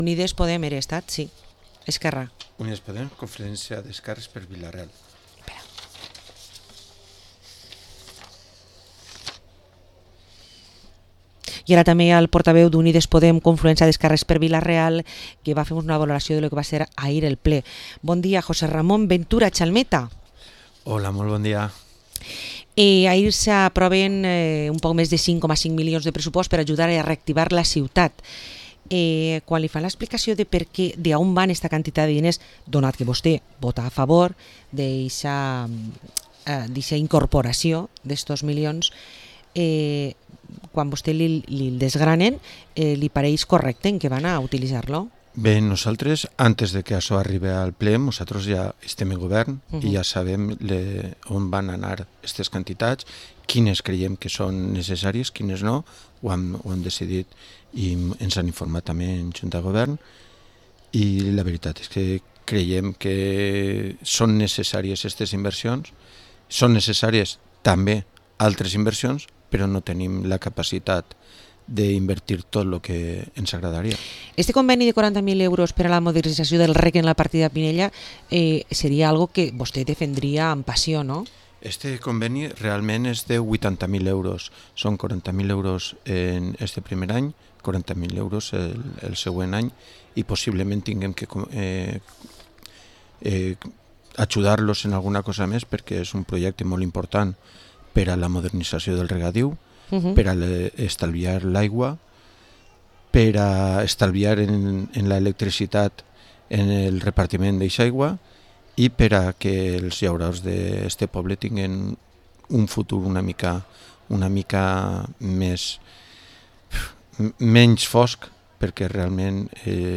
Entrevista al concejal de Unides Podem de Vila-real, José Ramón Ventura Chalmeta